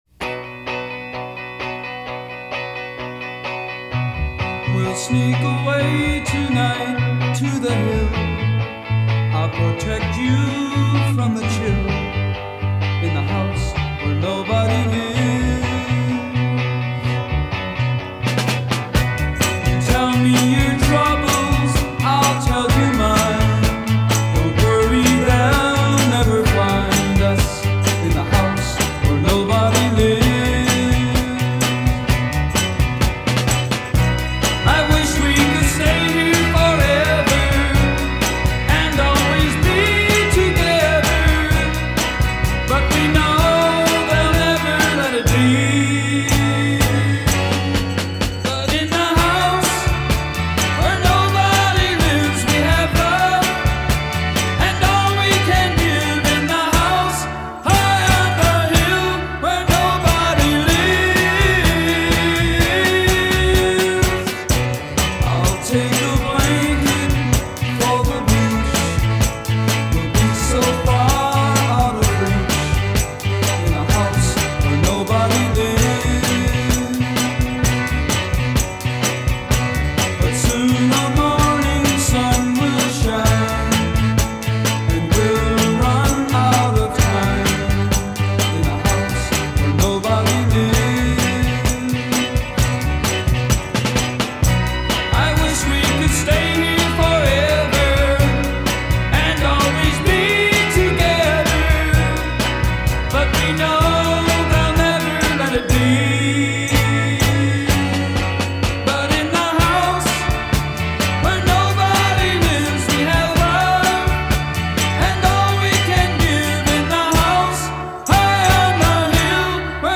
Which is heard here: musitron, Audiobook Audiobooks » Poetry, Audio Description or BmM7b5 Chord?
musitron